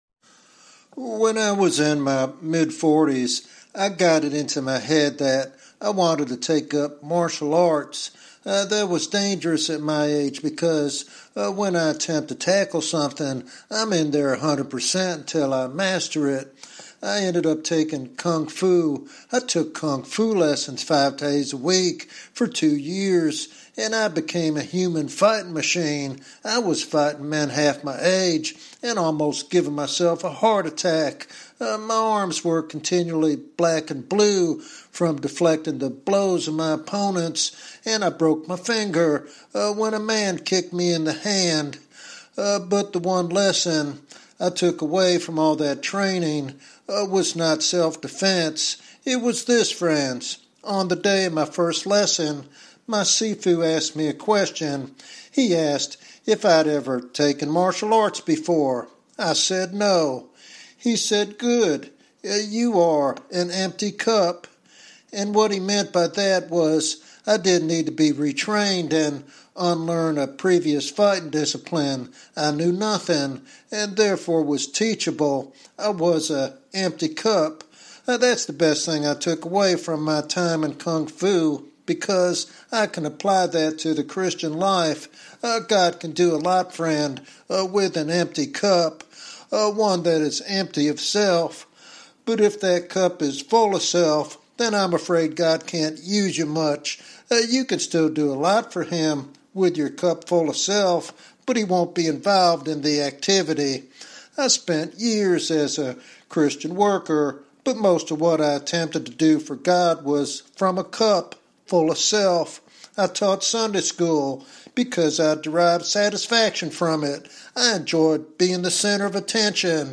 This sermon challenges believers to surrender their self-reliance and embrace the Spirit's power to impact lives and eternity.